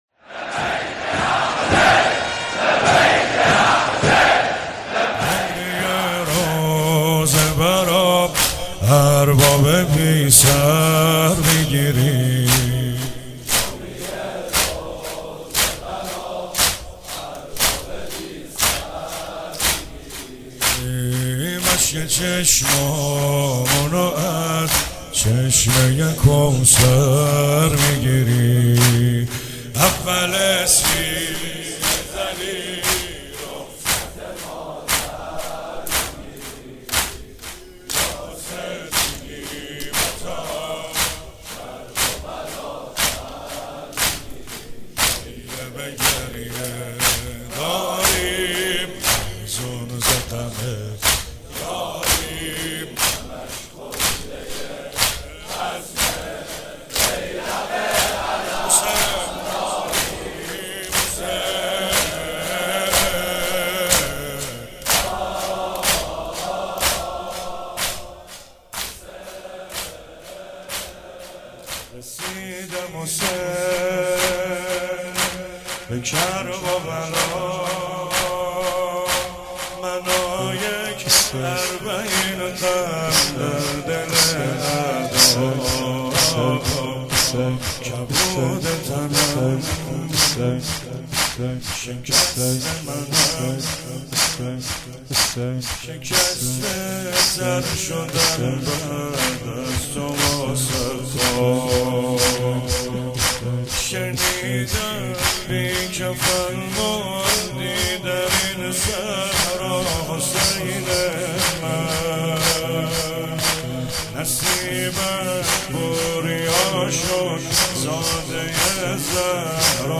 شب اربعین 96 - زمینه - رسیده حسین به کرب و بلا منو یک اربعین